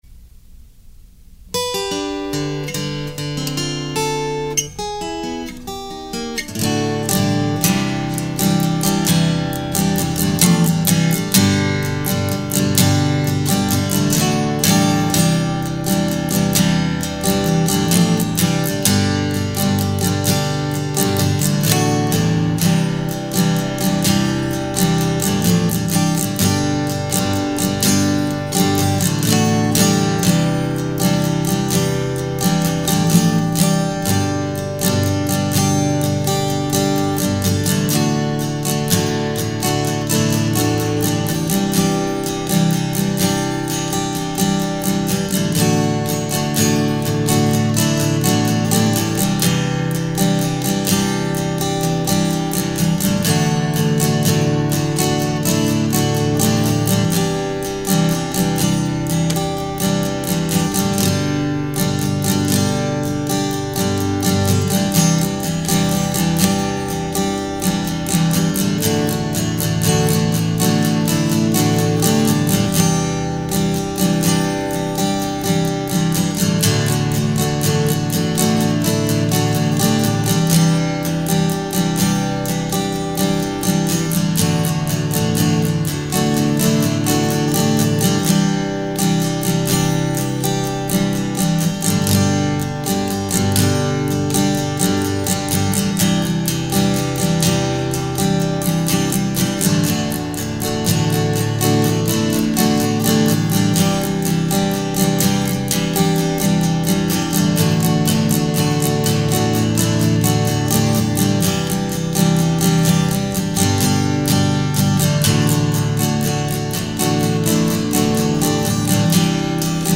Acoustic Guitar Instrumental
One live guitar track and two clones. I recorded this on a DP-03SD using one of the built-in mics on the front - in a reflective room.
The original guitar track is panned up the middle and dry.
The whole mix has a preset room reverb - just enough to be able to tell when it's not there.
There's a lot of pick noise and what I'm guessing is mic or room noise, so maybe move away from right where you strum.
I noticed the track is quite bright - almost brittle sounding.